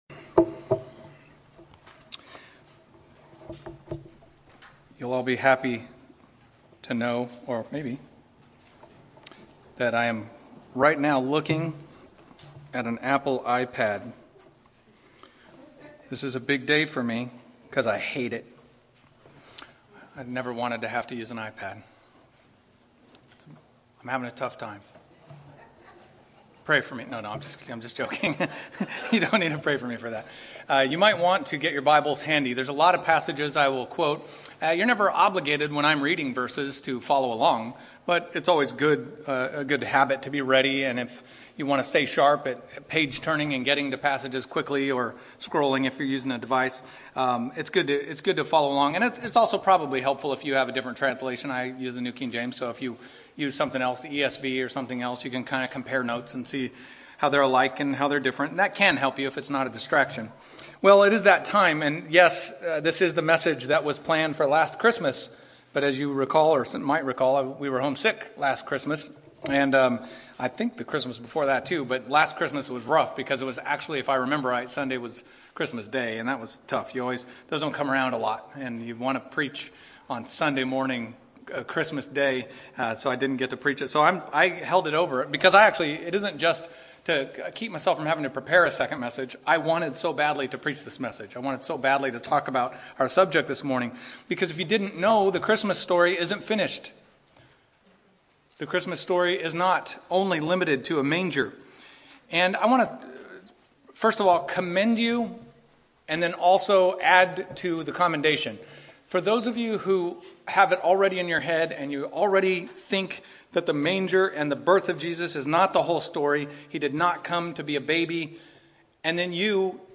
Sermons - Sovereign Grace Baptist Church